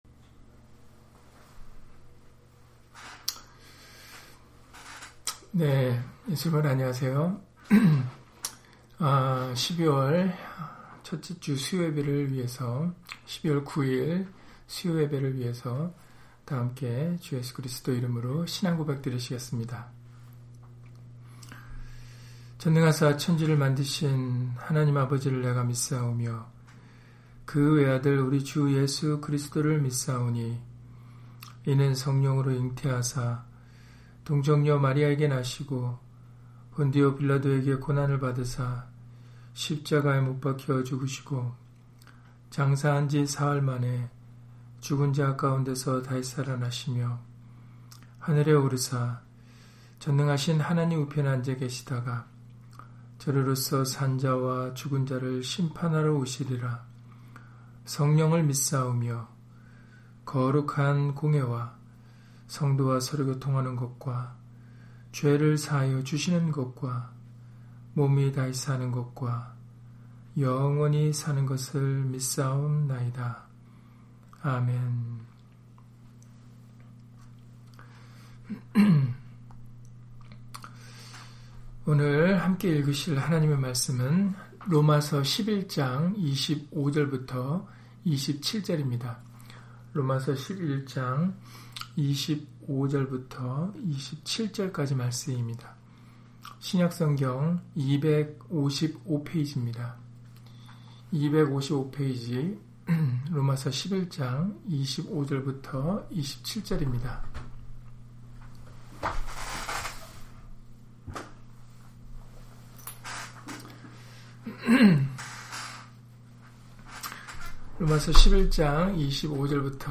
로마서 11장 25-27절 [우리의 구원을 위하여] - 주일/수요예배 설교 - 주 예수 그리스도 이름 예배당